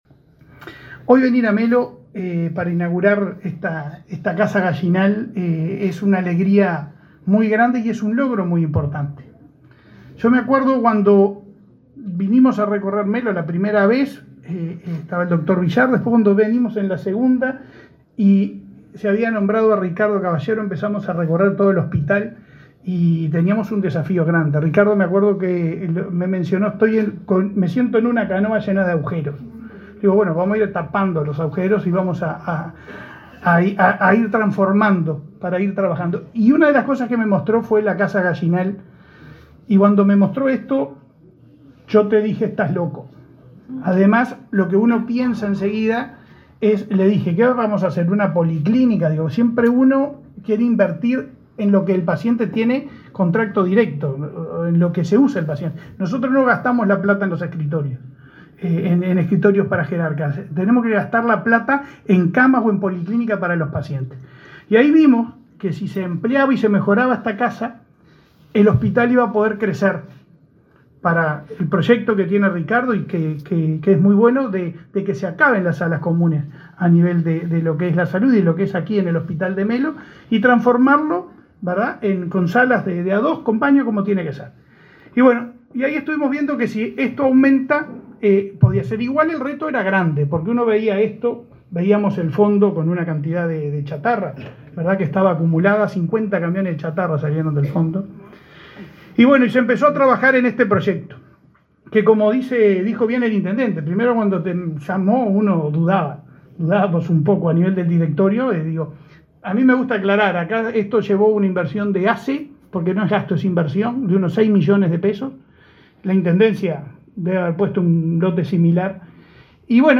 Palabras del presidente de ASSE, Leonardo Cipriani
Palabras del presidente de ASSE, Leonardo Cipriani 27/06/2023 Compartir Facebook X Copiar enlace WhatsApp LinkedIn El presidente de la Administración de los Servicios de Salud del Estado (ASSE), Leonardo Cipriani, participó, este martes 27 en Melo, en la inauguración de obras de remodelación en el edificio Gallinal, anexo al hospital local.